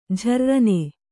♪ jharrane